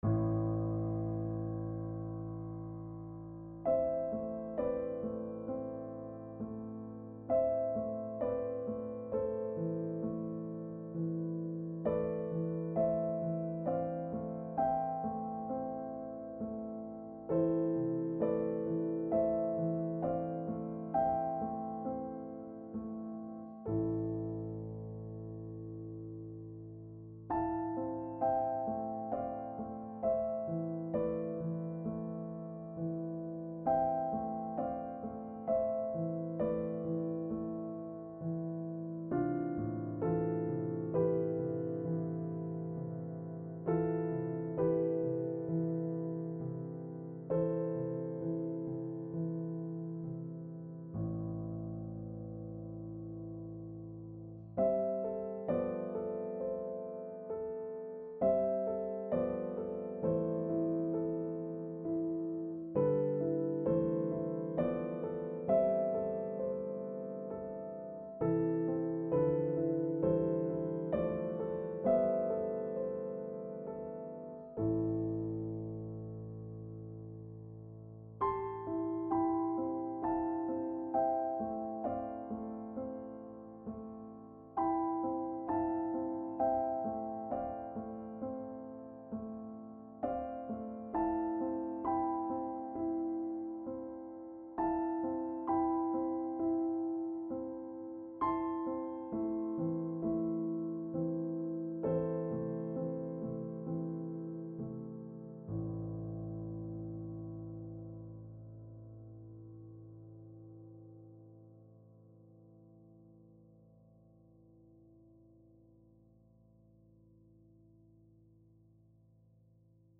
Instead of Christmas - Piano Music, Solo Keyboard - Young Composers Music Forum
Hello This is a very simple piece. I want to express how I think these weeks are going to be for me.